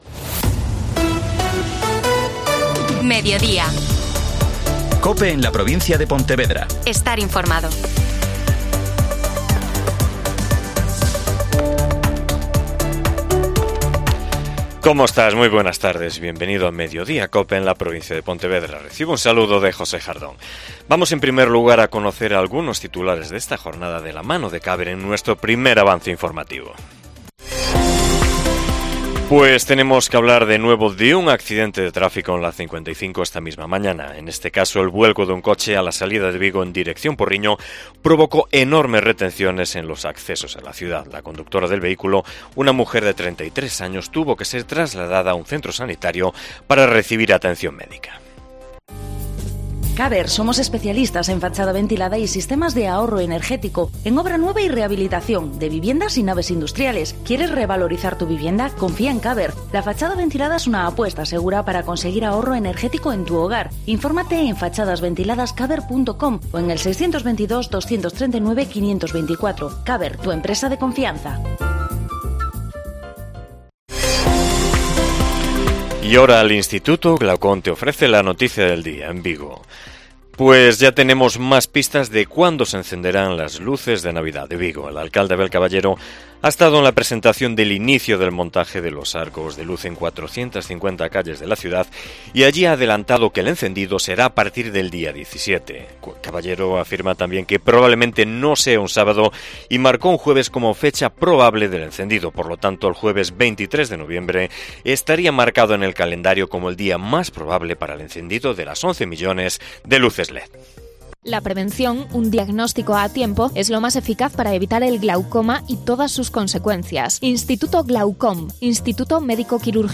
AUDIO: Magazine provincial